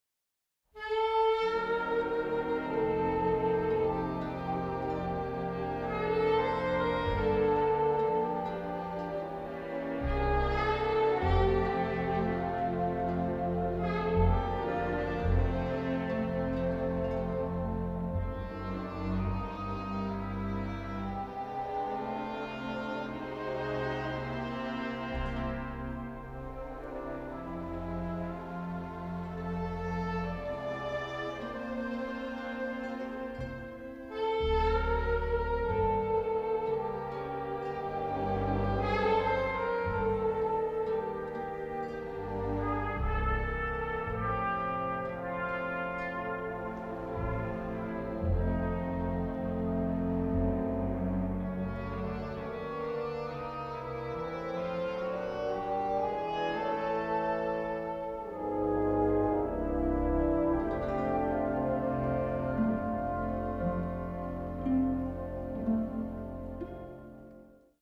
baritone.